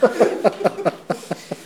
ajout des sons enregistrés à l'afk ...
rire-foule_04.wav